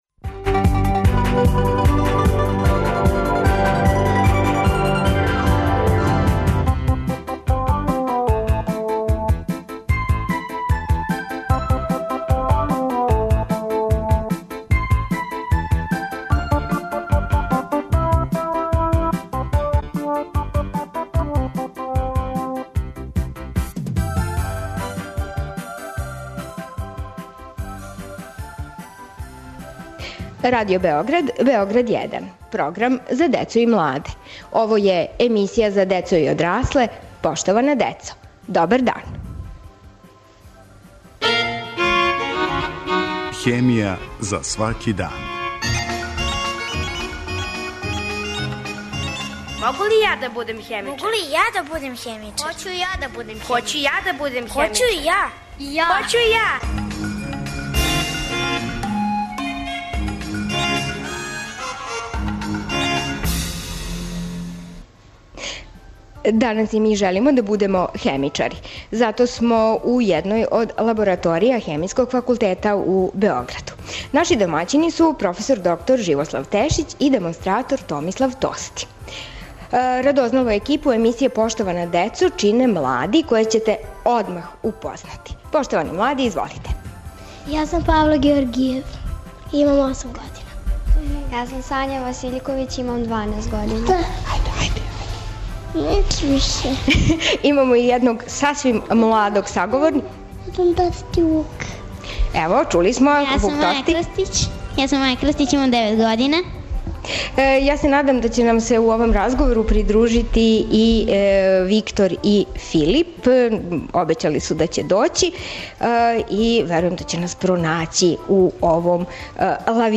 Емисија је реализована из једне од лабораторија у којој су млади упознали рад на једном истраживачком месту и били су у прилици да учествују у извођењу огледа.